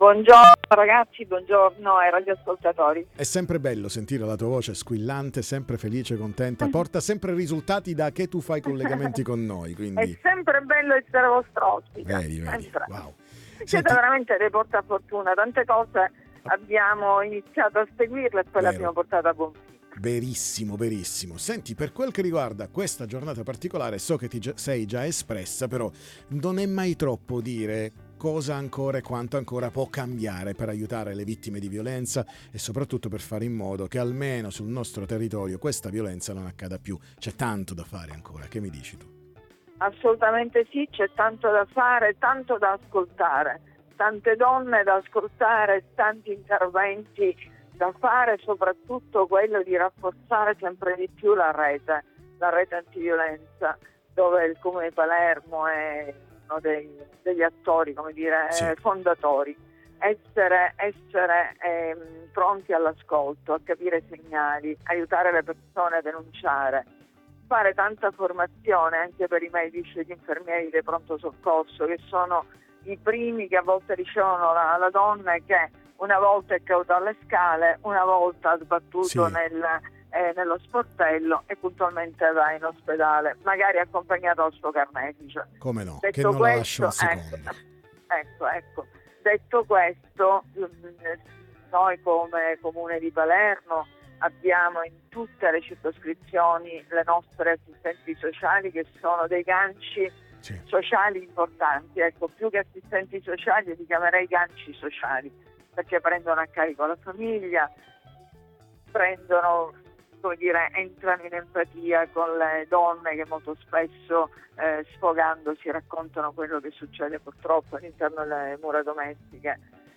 Giornata contro la violenza sulle donne 2025 Interviste Time Magazine 25/11/2025 12:00:00 AM